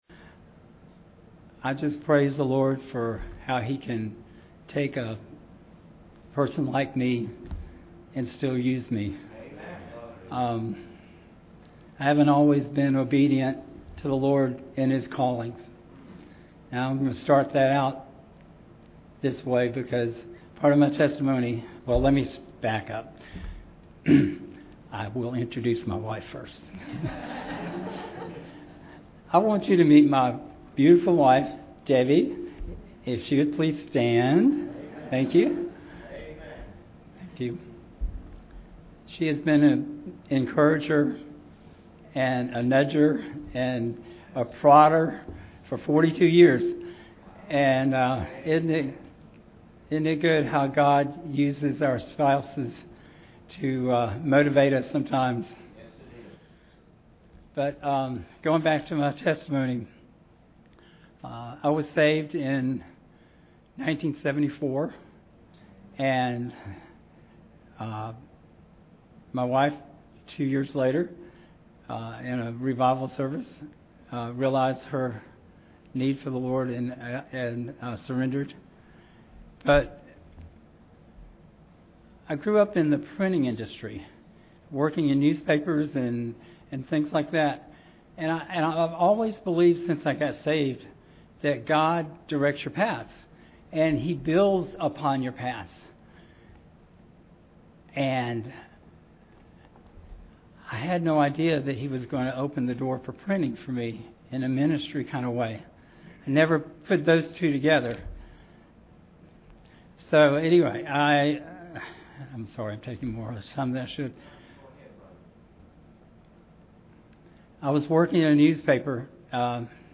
My Testimony
Series: 2016 Missions Conference